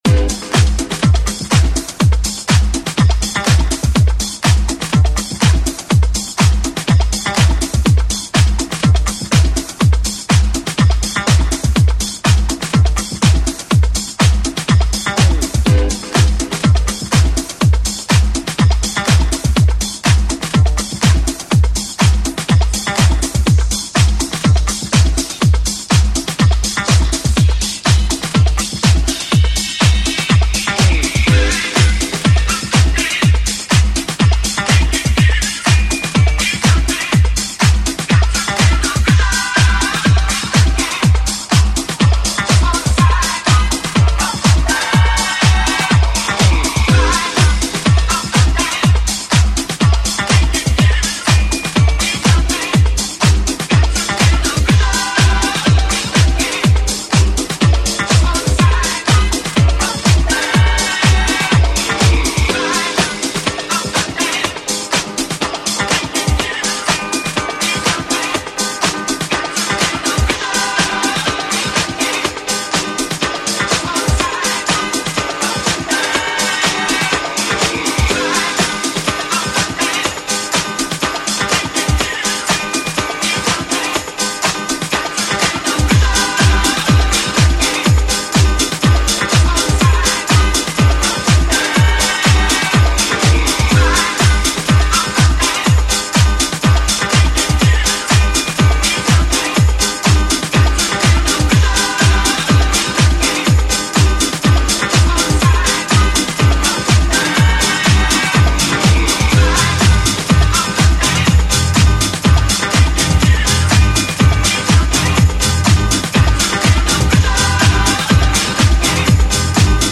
the funky feels continue